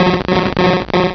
sound / direct_sound_samples / cries / krabby.aif